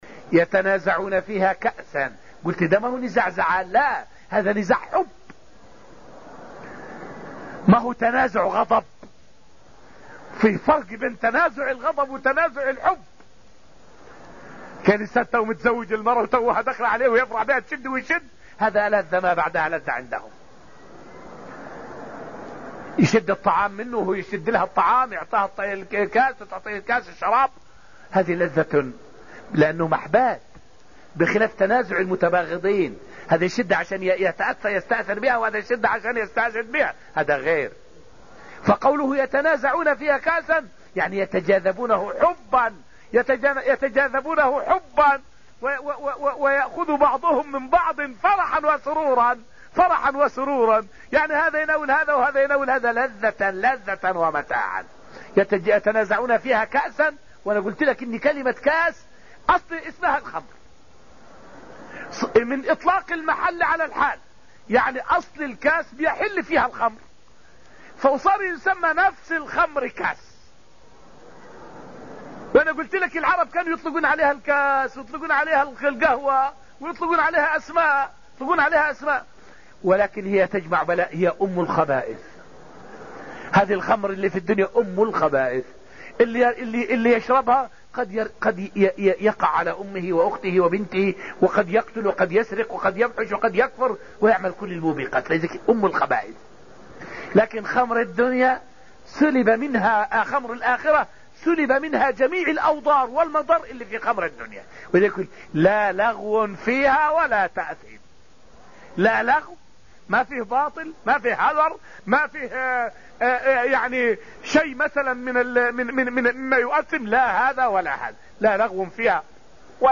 فائدة من الدرس الخامس من دروس تفسير سورة الطور والتي ألقيت في المسجد النبوي الشريف حول فضلُ الصحابة والرد على من يطعن فيهم.